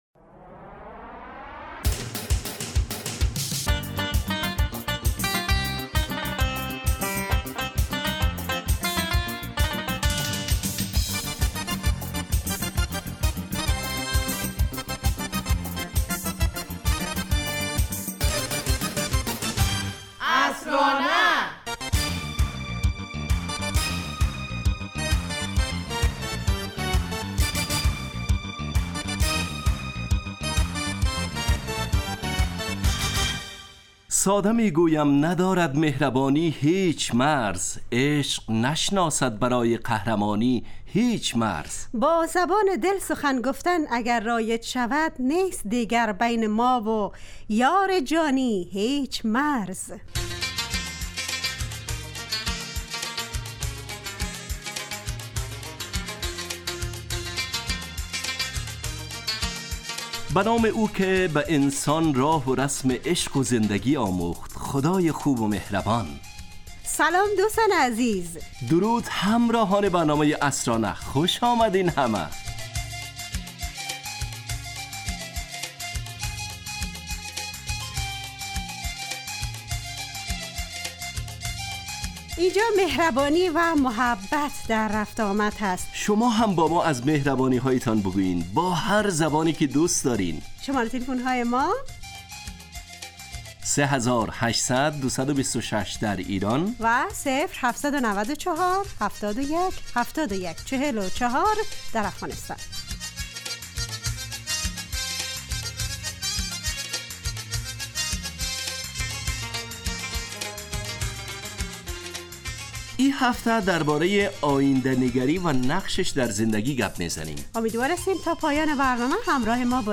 عصرانه برنامه ایست ترکیبی نمایشی که عصرهای جمعه بمدت 35 دقیقه در ساعت 17:25 دقیقه به وقت افغانستان پخش می شود و هرهفته به یکی از موضوعات اجتماعی و فرهنگی م...